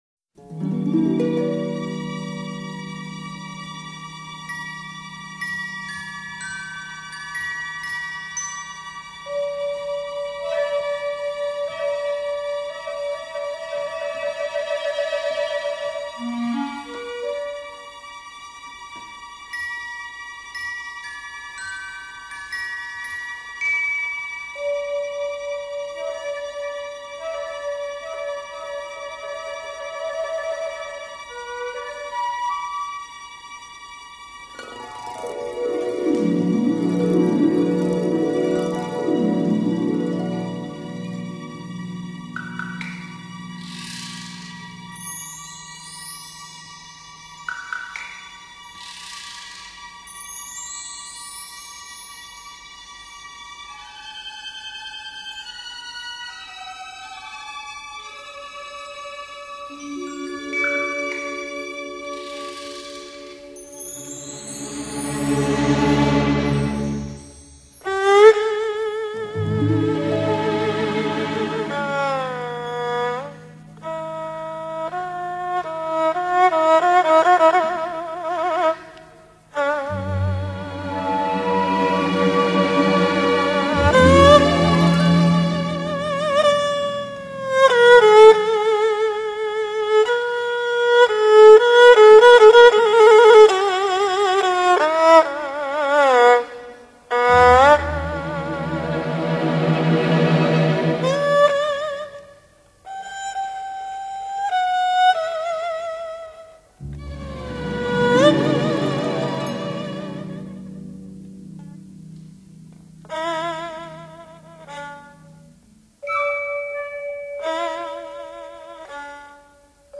二胡是中国最富有特点的民族乐器之一。
这首作品的旋律刚劲挺拔，跌宕起伏，铿锵有力，气势磅礴，是一首充满的赞歌。